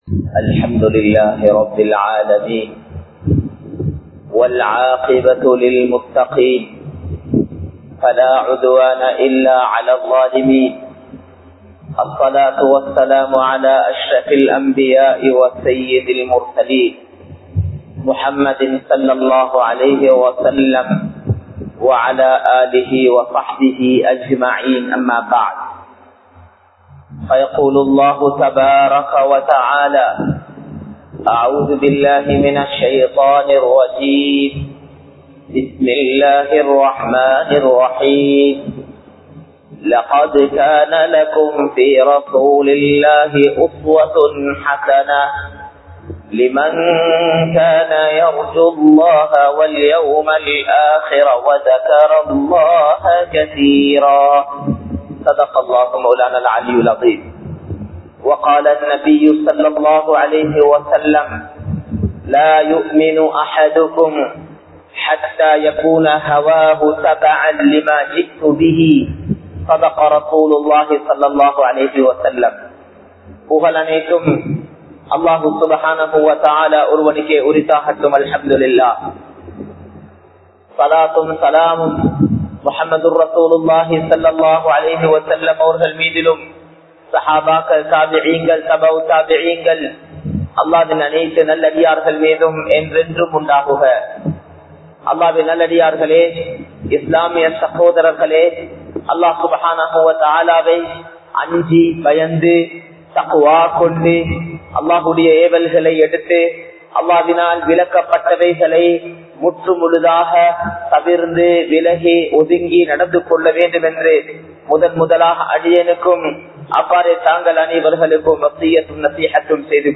நபி(ஸல்) அவர்களைப் பின்பற்றுவோம் | Audio Bayans | All Ceylon Muslim Youth Community | Addalaichenai